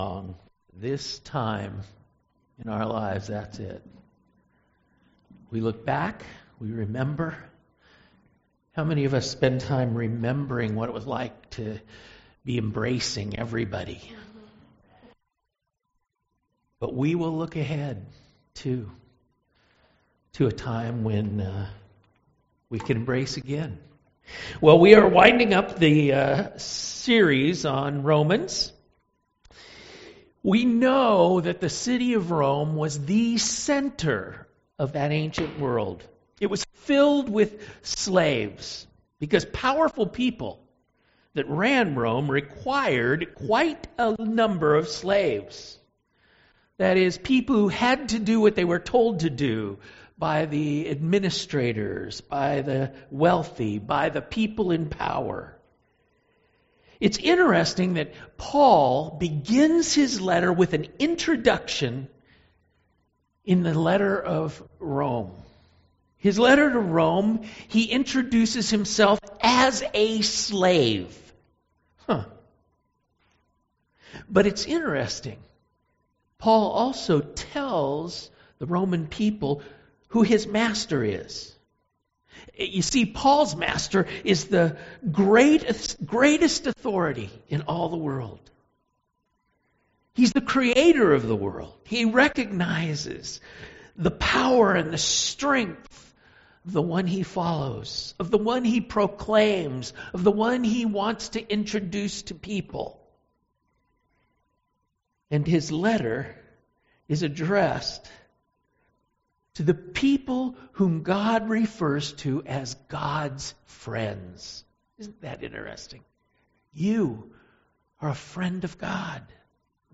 Sermon Audio Archives | Church of Newhall